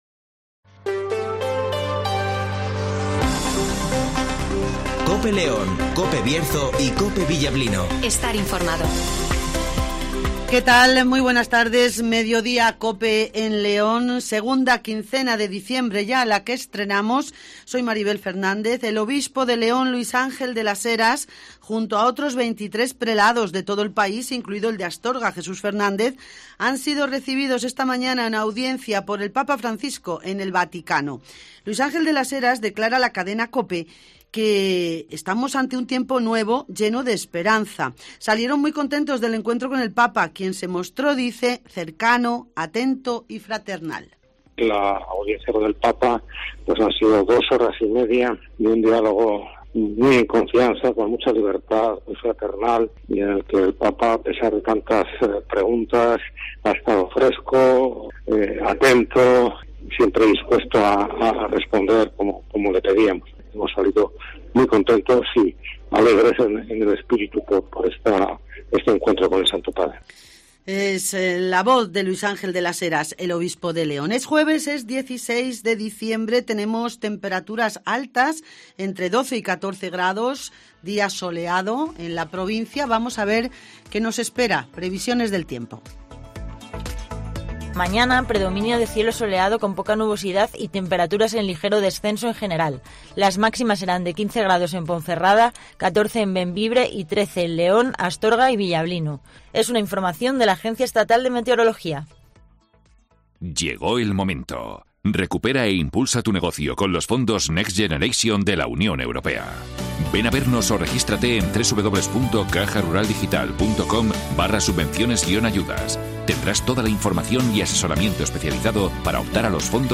- D.M. Luís Ángel de las Heras ( Obispo de la Diócesis de León " ) nos cuenta la Audencia con el Papa
- Voces de Niños Vacunados
- Ester Muñóz ( Delegada Territorial de la Junta en León )